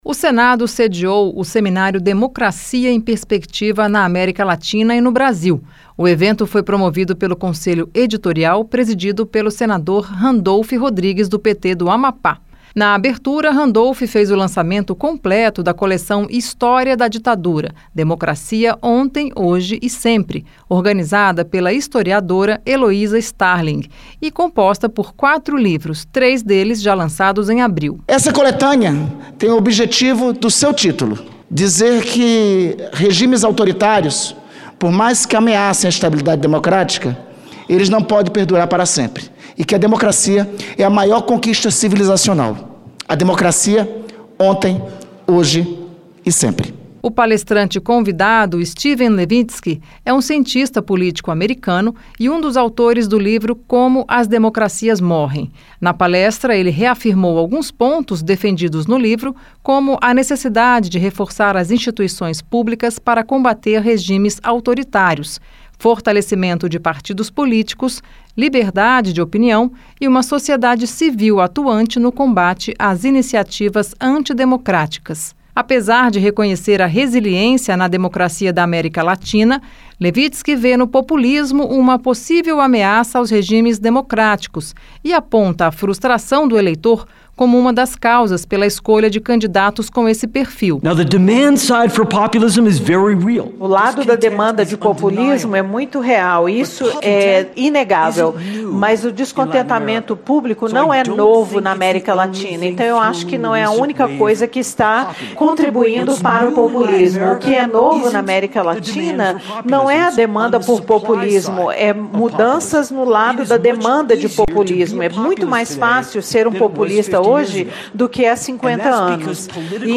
Seminário
O Senado promoveu nesta terça-feira (12) o seminário Democracia em perspectiva na América Latina e no Brasil. O palestrante foi o cientista político americano Steven Levitsky, um dos autores do livro "Como as democracias morrem".